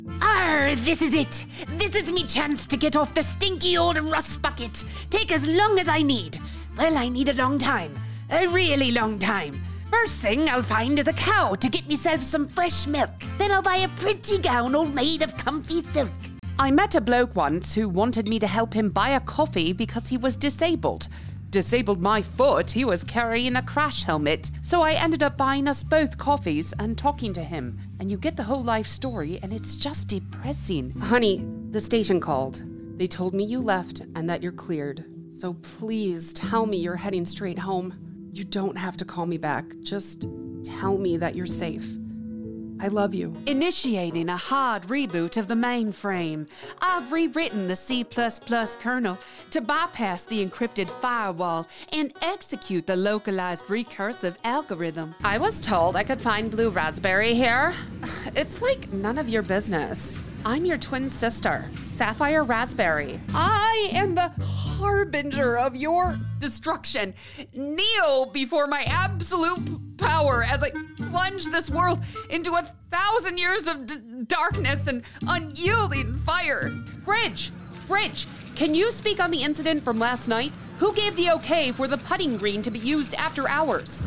Female
I've got a lower register female voice but I'm a soprano singer, so my range is expansive. Great with accents including British, Australian, southern American and Irish.
Character / Cartoon
Character Voices
All our voice actors have professional broadcast quality recording studios.
0227Character_Demo.mp3